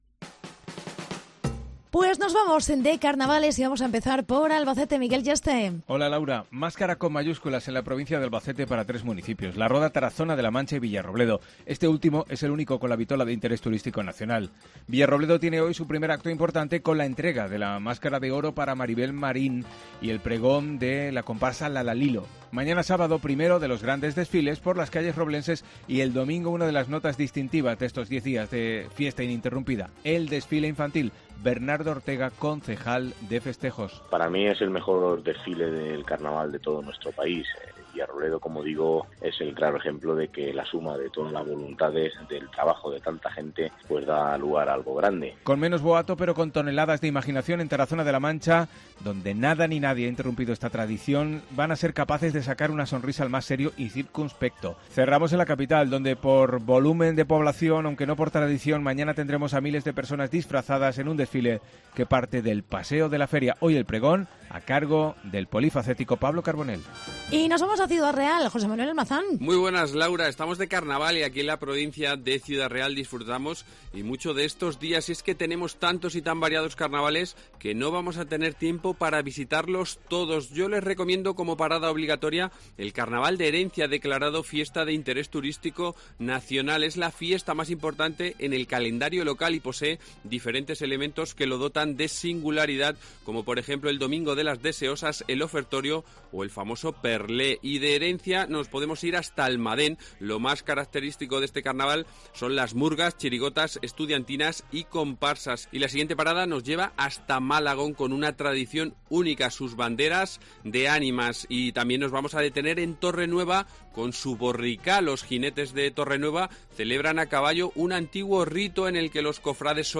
Recorremos la región de la mano de nuestros compañeros de COPE en las distintas provincias